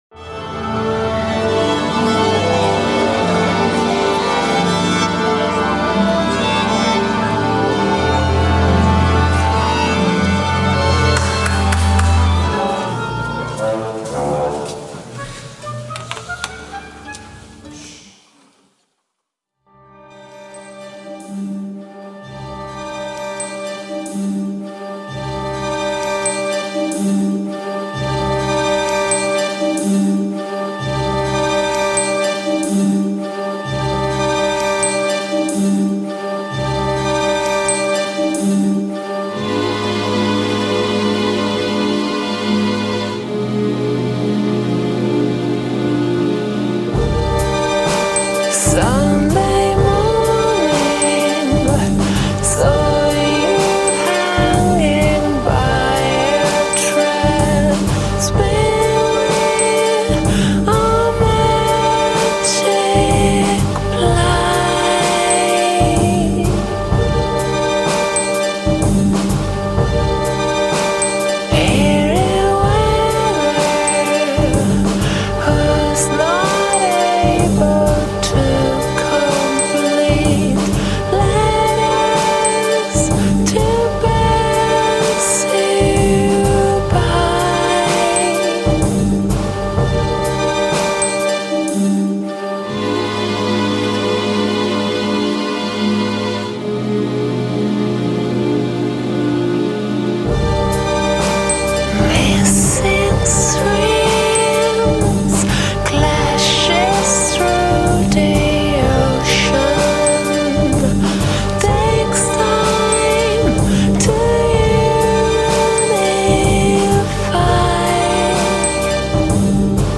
A tasty Trip-Hop podcast